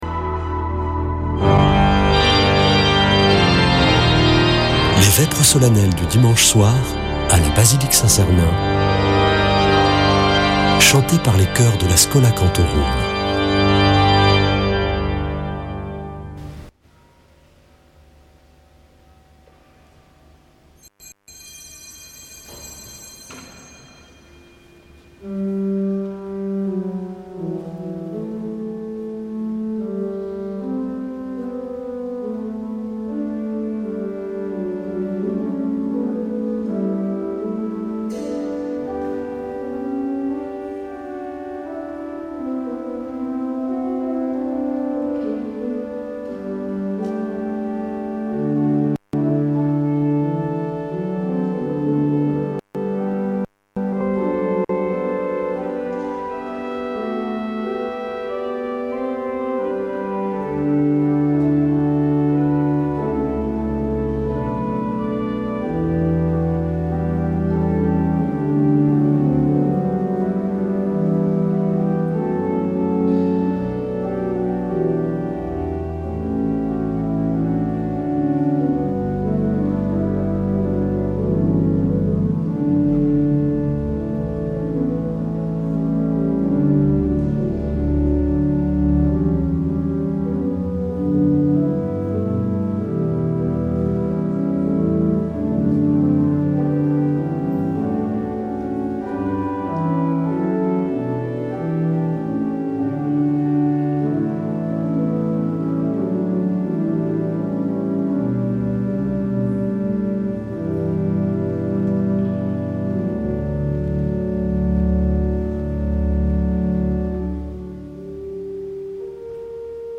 Vêpres de Saint Sernin du 19 oct.